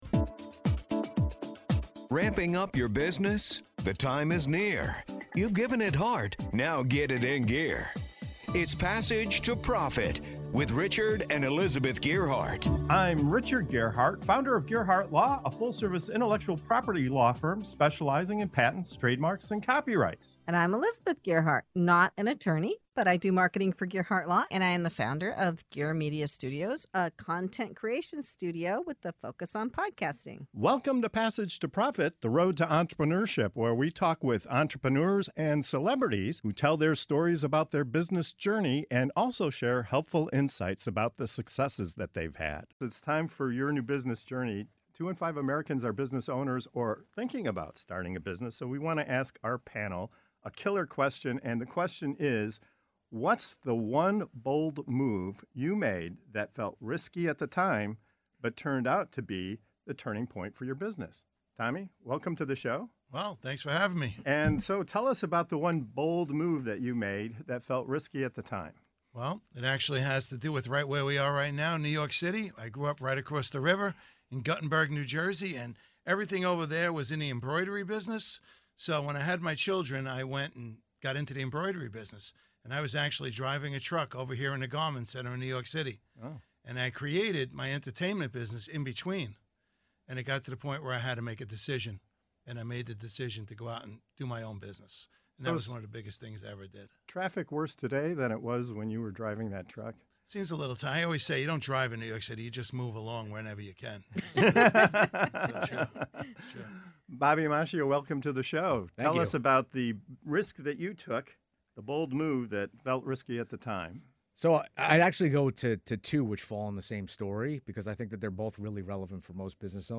In this inspiring segment of "Your New Business Journey" on Passage to Profit Show, our panel of entrepreneurs reveals the gutsy decisions that took them from comfort zones to game-changing success. From walking away from family empires and corporate careers to starting from zero with kids and just a few months of savings, each story proves that real growth begins with risk.